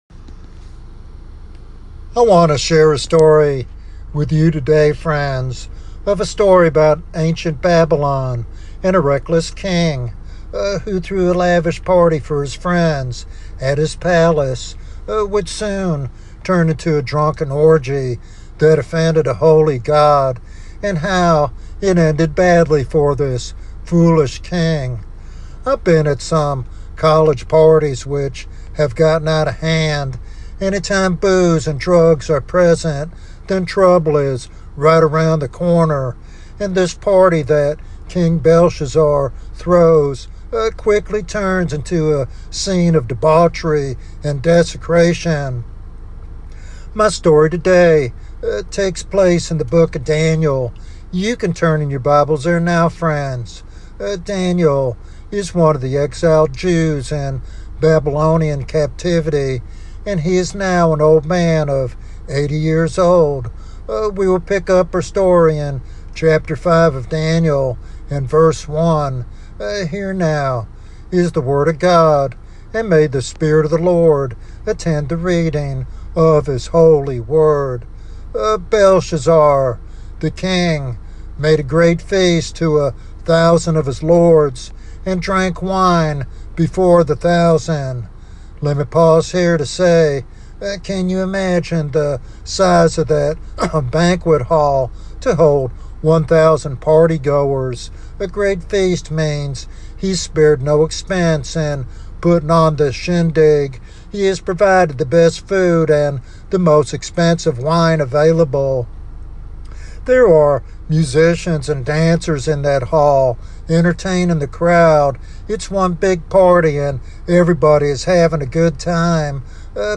Revival Sermon Ghostly Hand